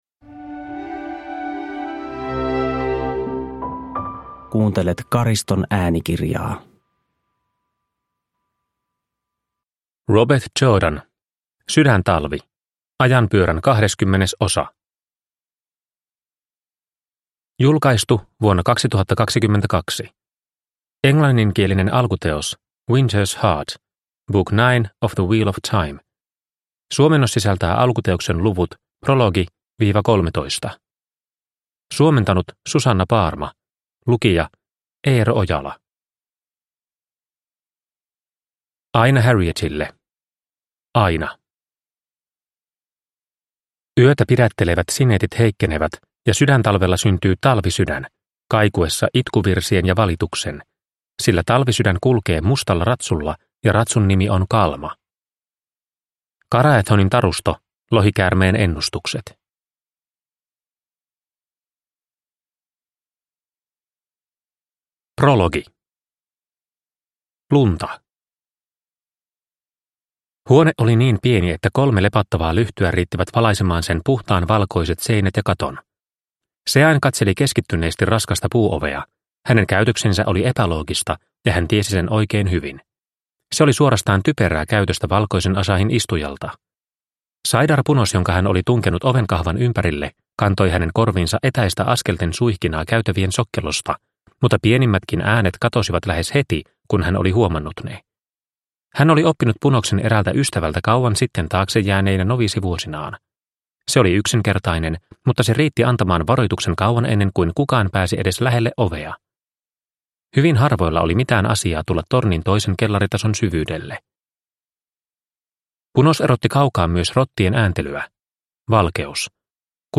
Sydäntalvi – Ljudbok – Laddas ner